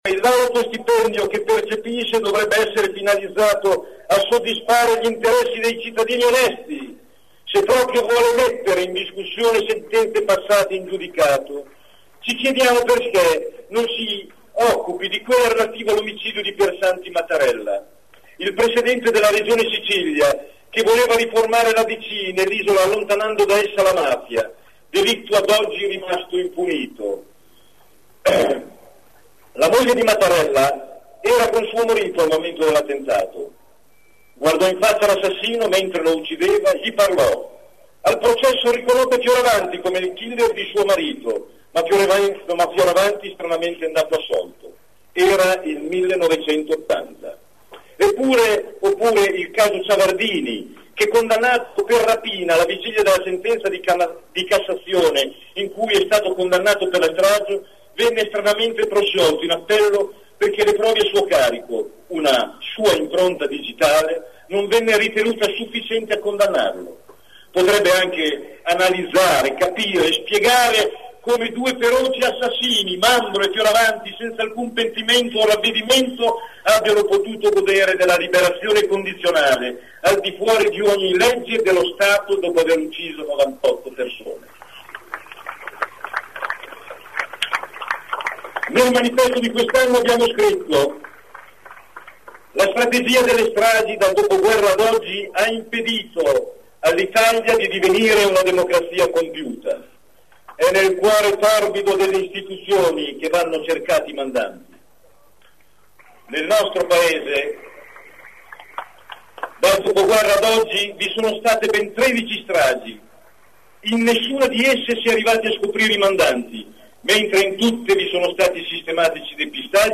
Foto panoramica clicca e tieni premuto per muovere Tantissime persone nell’anniversario della strage alla stazione di Bologna.